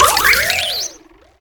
Cri d'Ogerpon dans Pokémon HOME.